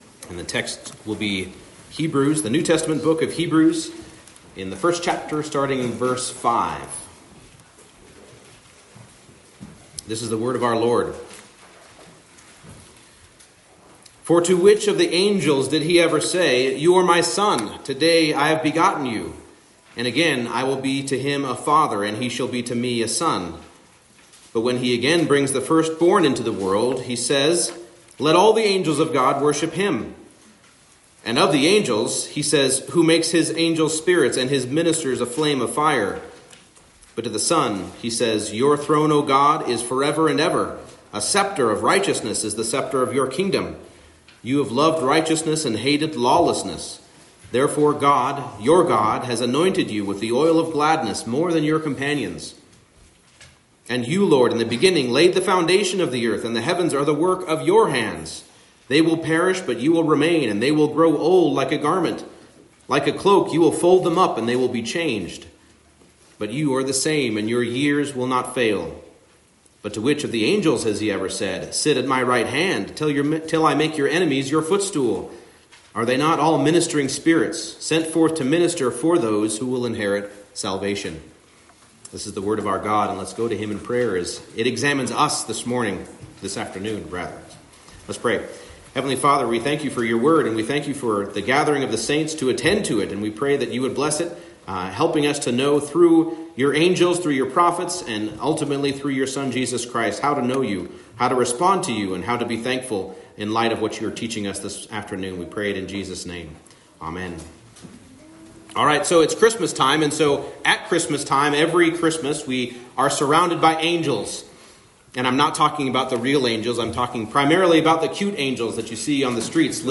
Service Type: Special Worship Service